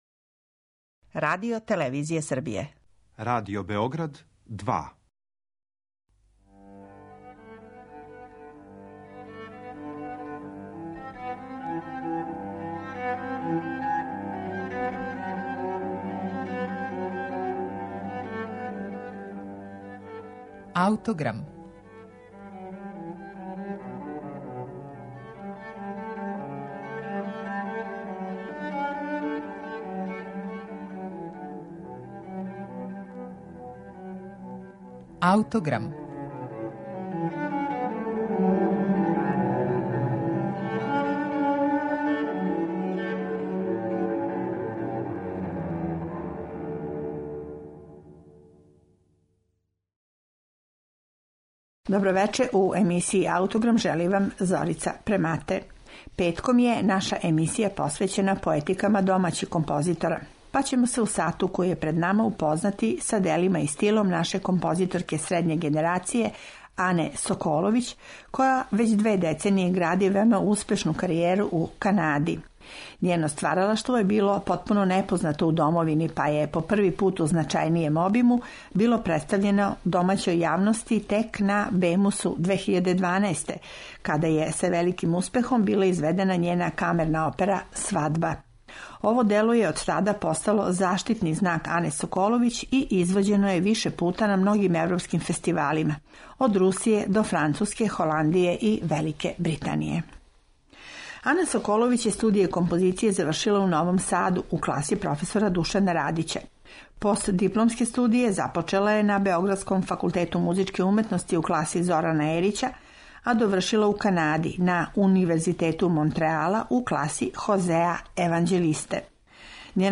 а одсвираће је чланови Ансамбла за нову музику Монтреала, коме је ово дело и посвећено